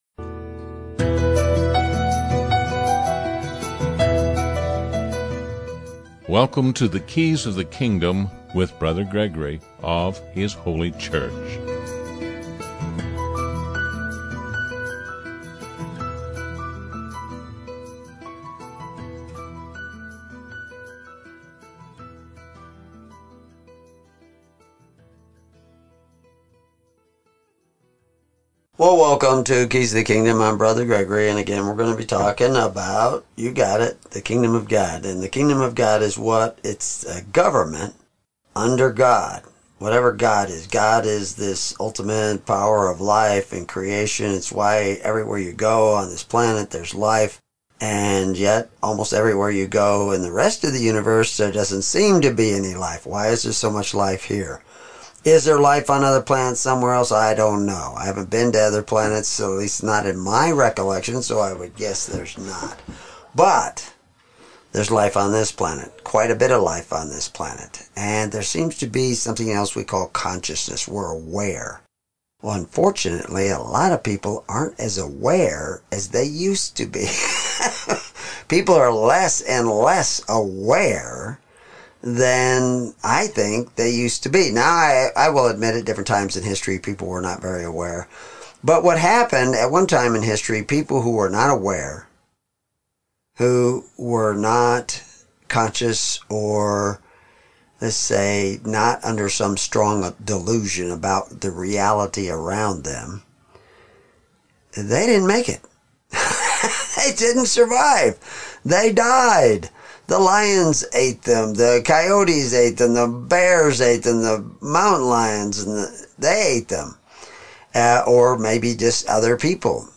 Comments Download Recording 1 Corinthians 13 broadcast "Though I speak .